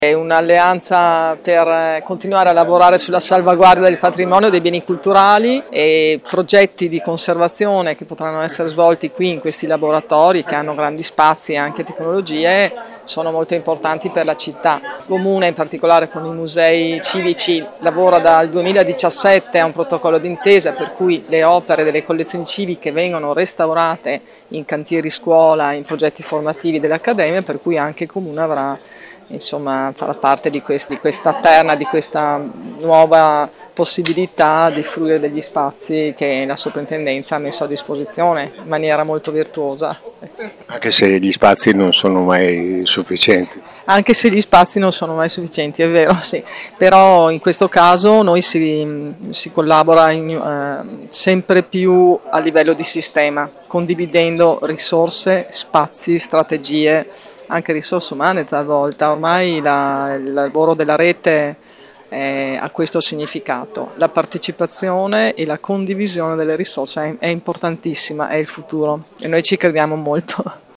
Ai nostri microfoni si sono espressi alcuni dei responsabili di questo grande risultato: